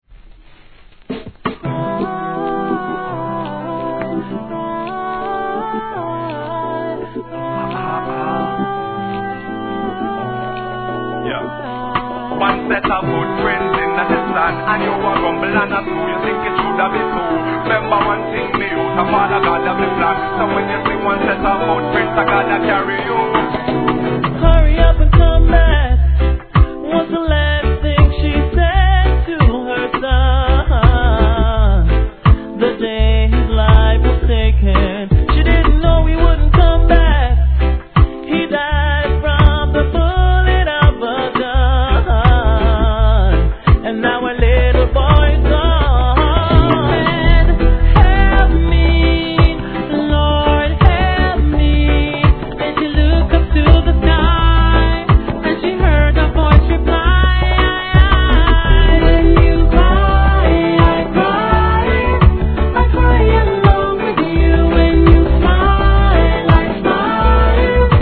REGGAE
ミディアムの超BIG HITリディム!!